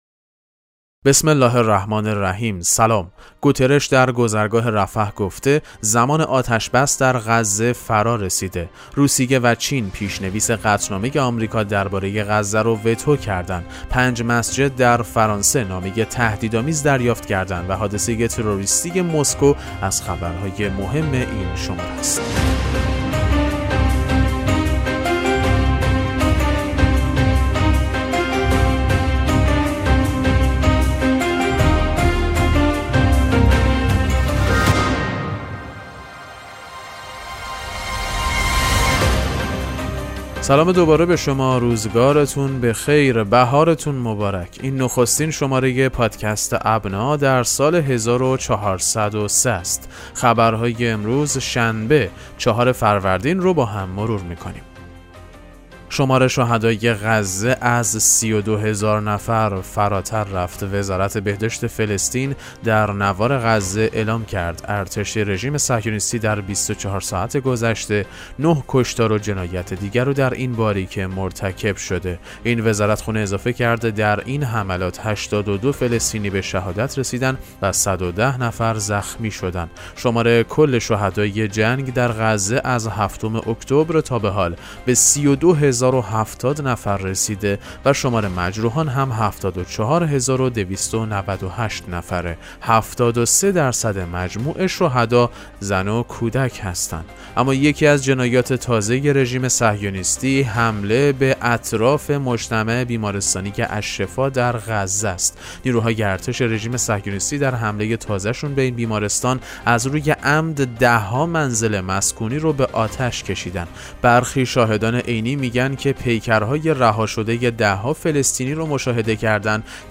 پادکست مهم‌ترین اخبار ابنا فارسی ــ 4 فروردین 1403